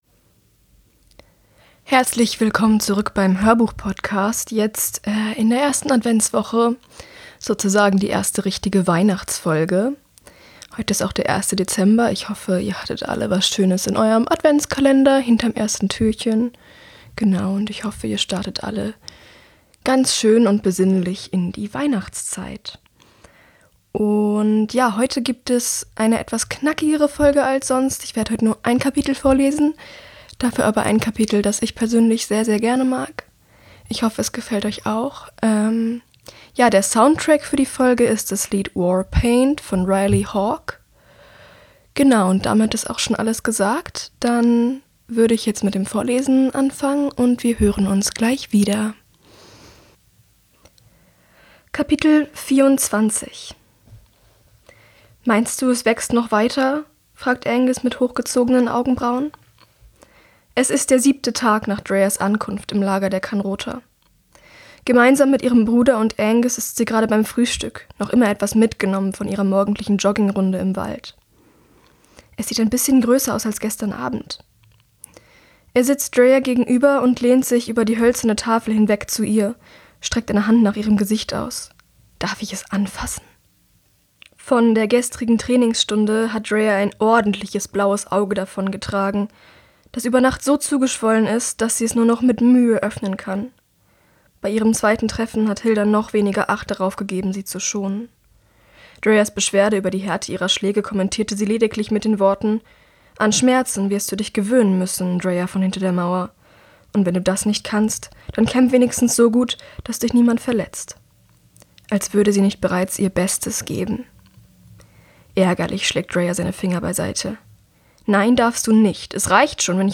Immuna X - Der Hörbuchpodcast